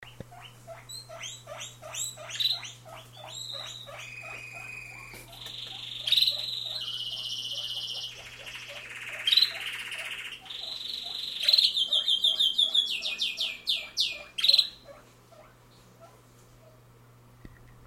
This is a snippet in which you hear our guinea pigs as well as the two birds. It's short but shows the everyday life in our living-room